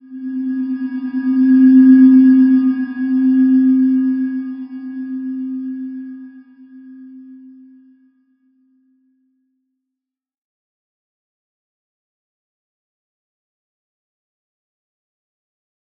Slow-Distant-Chime-C4-f.wav